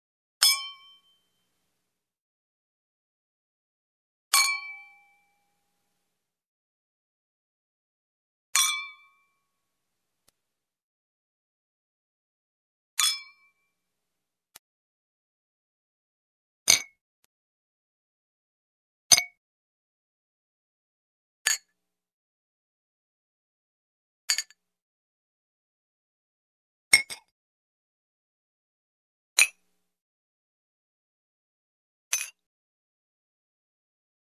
Посуда звуки скачать, слушать онлайн ✔в хорошем качестве